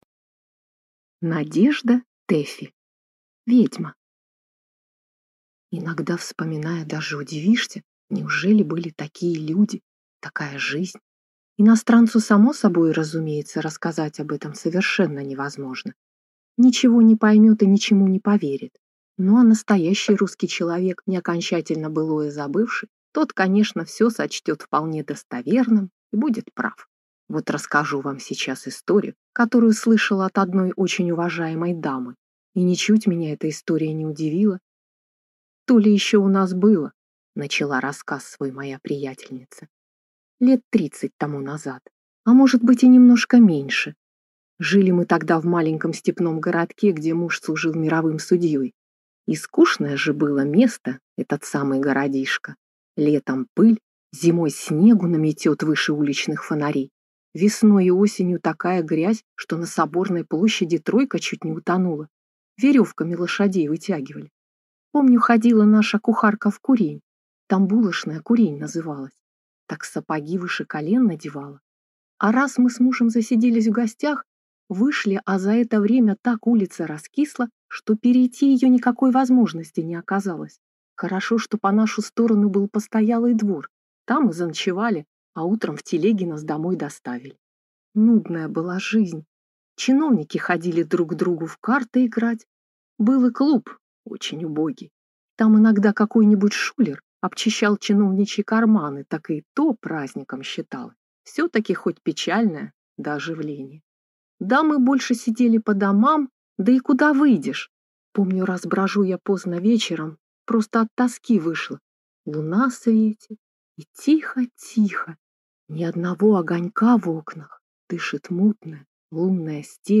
Аудиокнига Ведьма | Библиотека аудиокниг